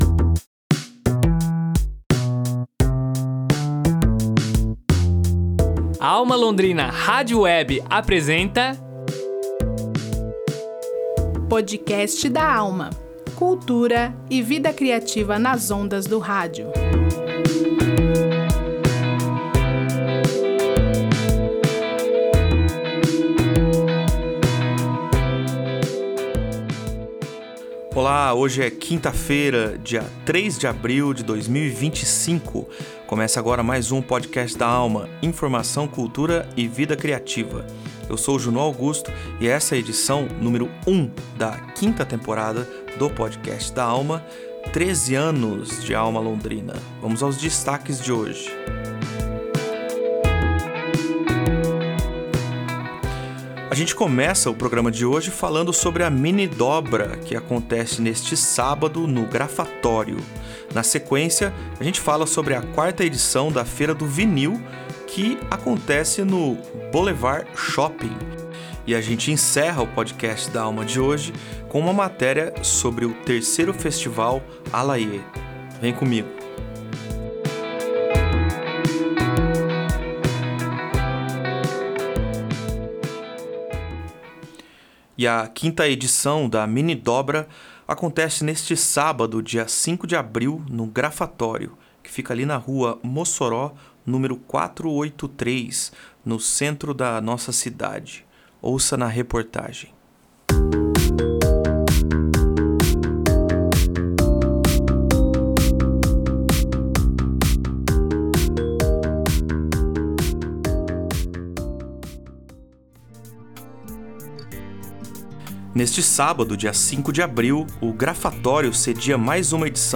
Jornalismo Cultural